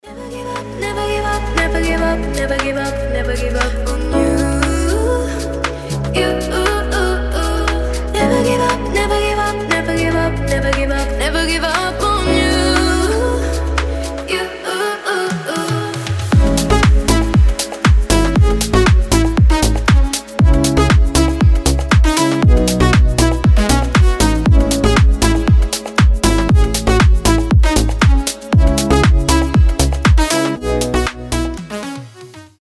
• Качество: 320, Stereo
красивые
deep house